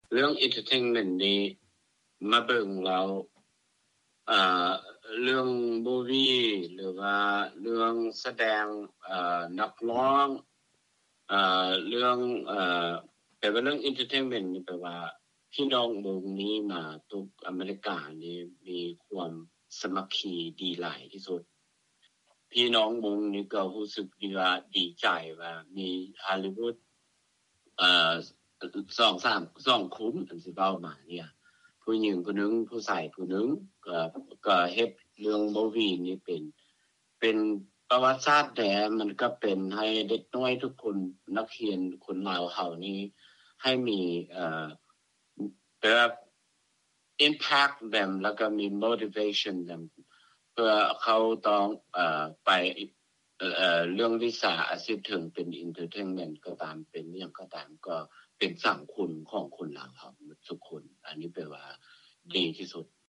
ຄົນອາເມຣິກັນເຊື້ອສາຍມົ້ງທ່ານນຶ່ງຈາກລັດຄາລິຟໍເນຍ ໄດ້ສະແດງຄວາມຮູ້ສຶກ ກ່ຽວກັບ ການຜະລິດຮູບເງົາ The Harvest ຕໍ່ພວກເຮົາວ່າ
ຊາວມົ້ງ ໃນລັດວິສຄອນຊິນ.